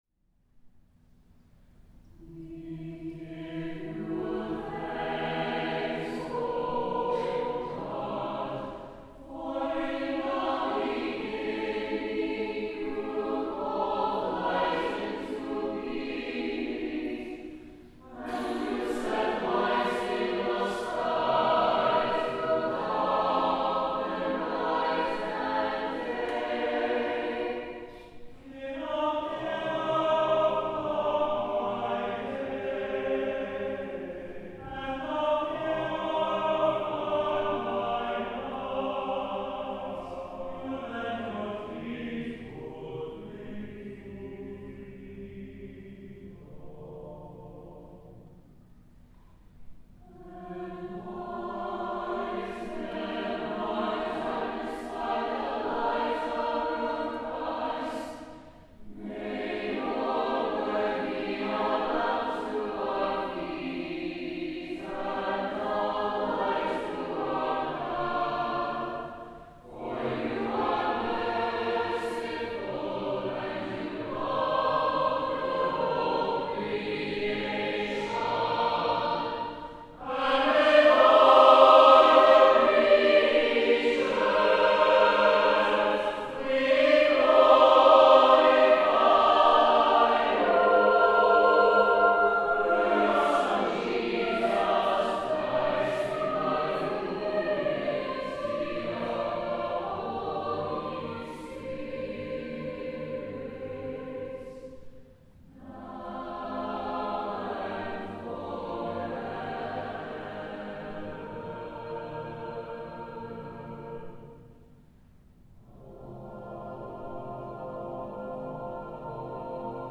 SATB divisi